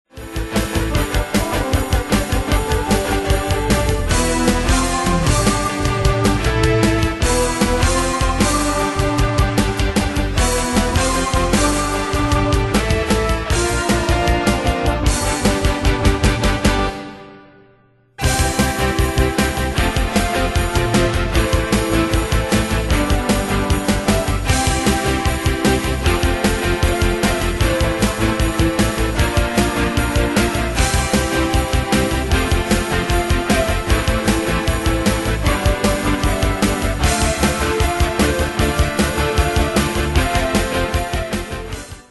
Danse/Dance: Rock Cat Id.
Pro Backing Tracks